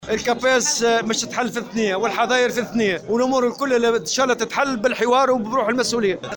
أكد الأمين العام للاتحاد العام التونسي للشغل، نور الدين الطبوبي، في تصريح